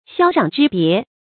霄壤之别 xiāo rǎng zhī bié
霄壤之别发音